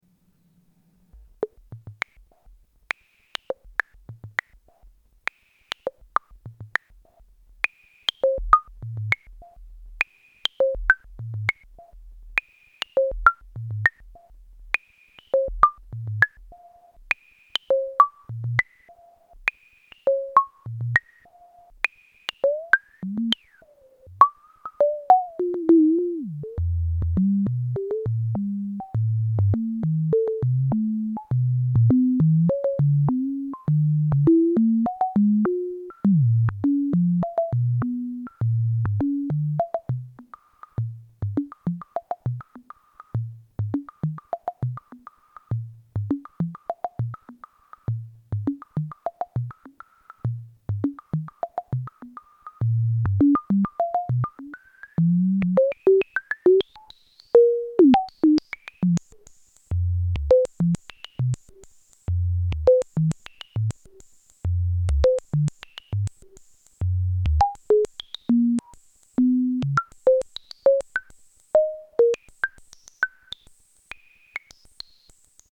3. VCF triggered with impulses
the VCF, generating 'ping' like sounds. Here
Drum machine
NoiseVCA_VCFtriggered-535.mp3